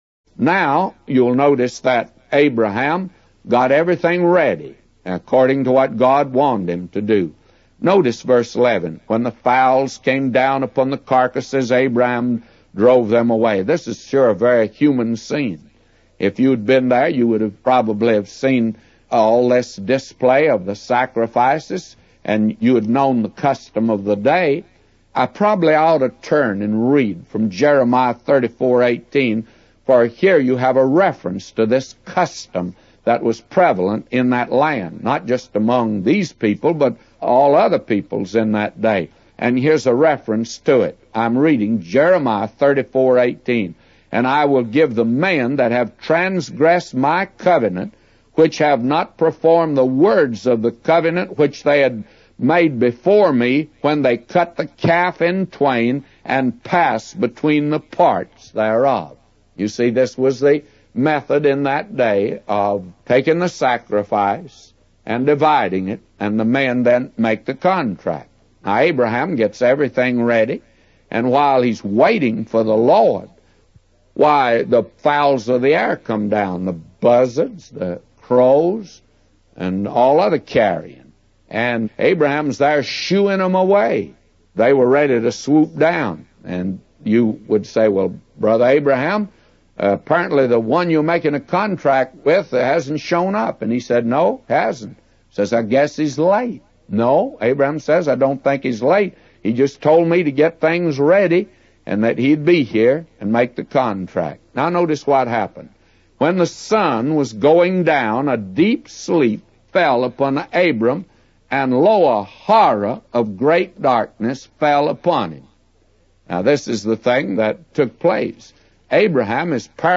A Commentary By J Vernon MCgee For Genesis 15:11-999